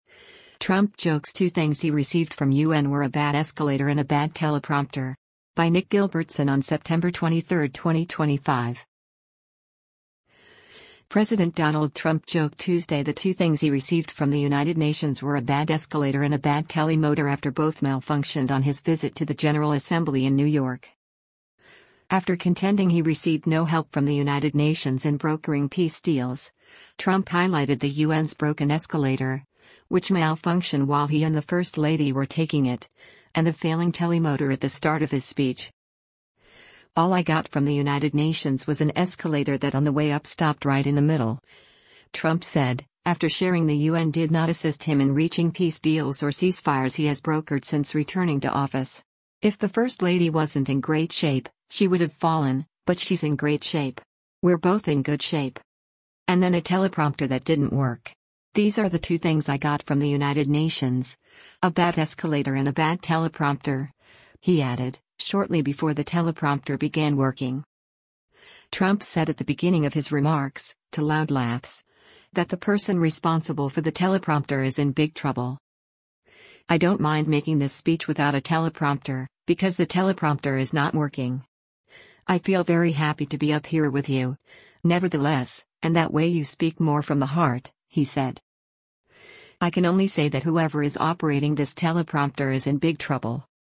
NEW YORK, NEW YORK - SEPTEMBER 23: U.S. President Donald Trump speaks during the 80th sess
Trump said at the beginning of his remarks, to loud laughs, that the person responsible for the teleprompter “is in big trouble”